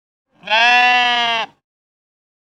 sheep-bleet.wav